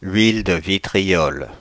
Ääntäminen
Synonyymit vitriol Ääntäminen France (Paris): IPA: /ɥil də vi.tʁi.ɔl/ Haettu sana löytyi näillä lähdekielillä: ranska Käännöksiä ei löytynyt valitulle kohdekielelle.